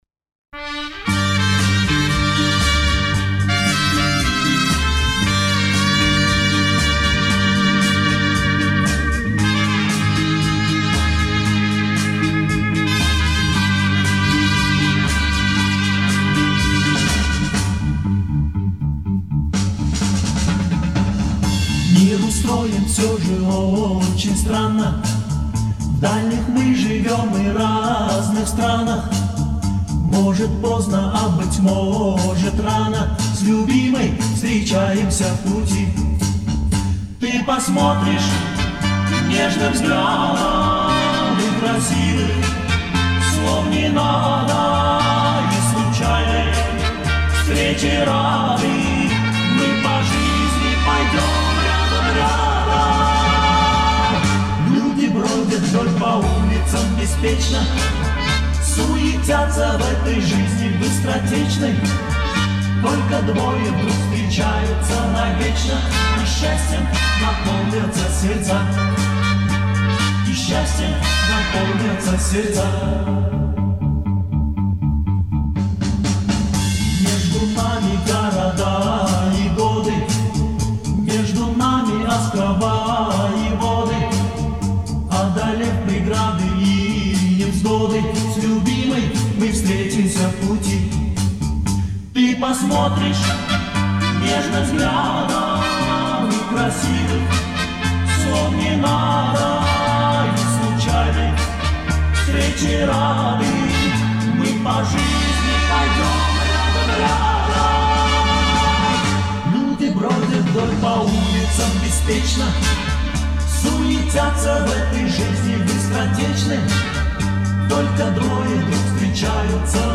И аранжировка отличается.